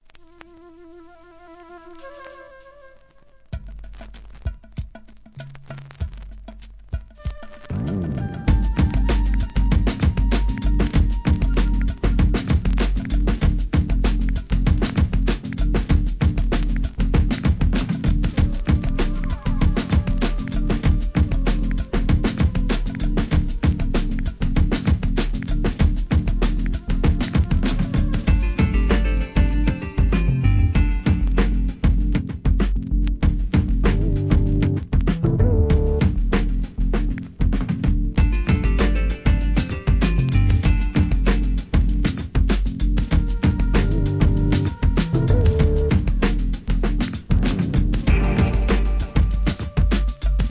Future Jazz、ラテン、ブレイクビーツ